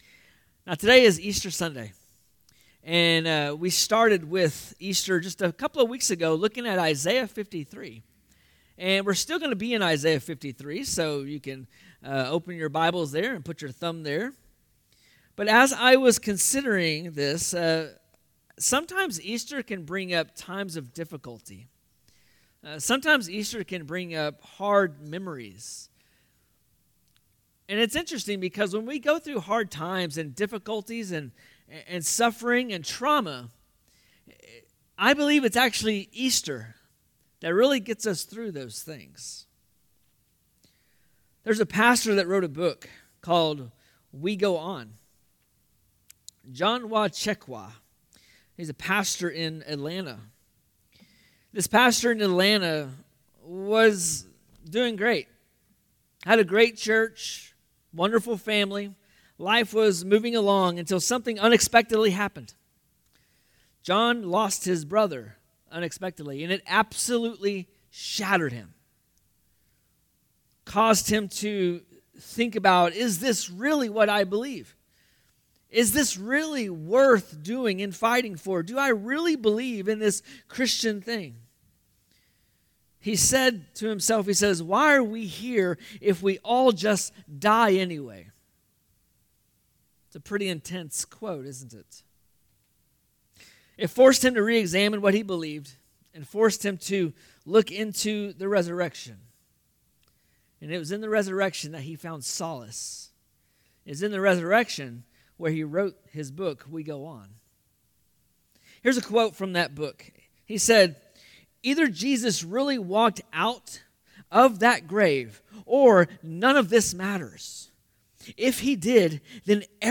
Current Sermon